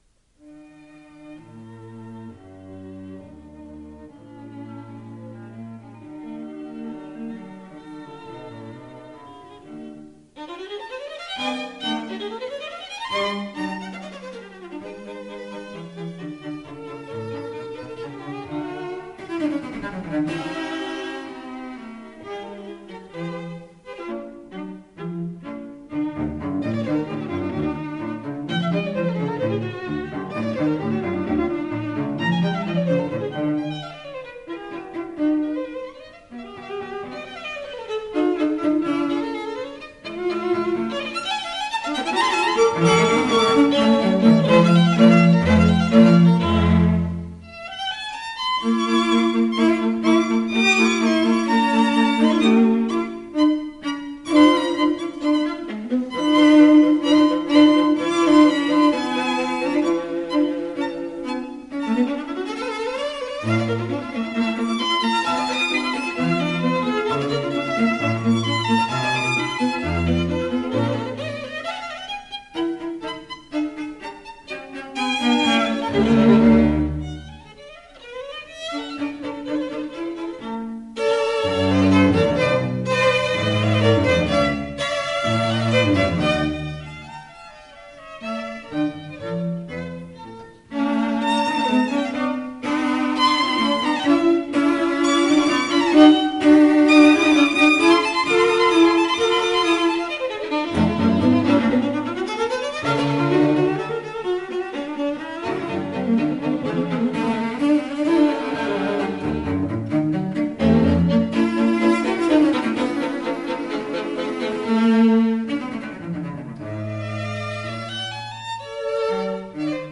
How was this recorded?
Williamstown MA USA 1983